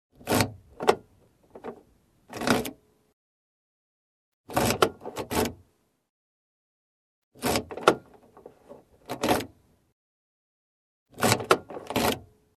Звуки двери автомобиля